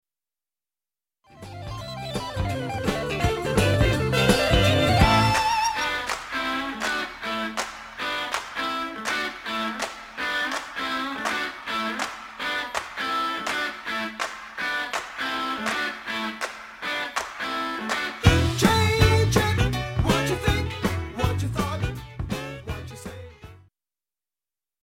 Riff – public domain